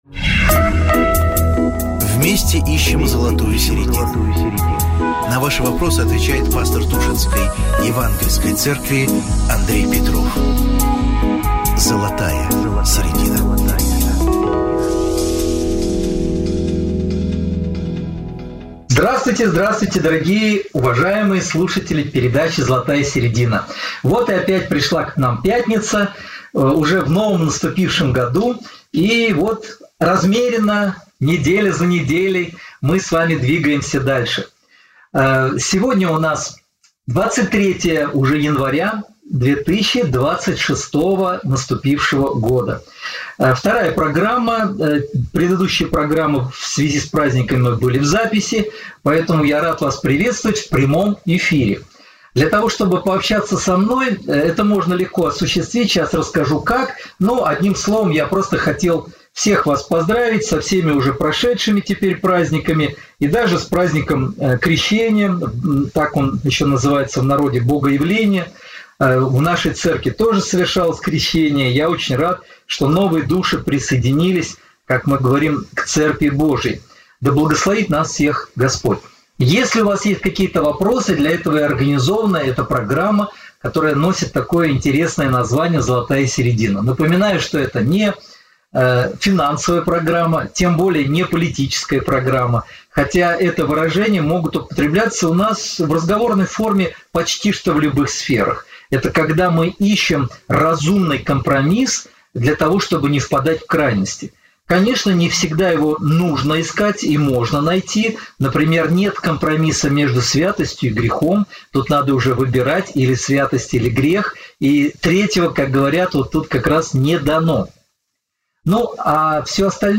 Размышления на актуальные темы и ответы на вопросы слушателей.